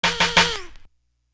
Craze Perc.wav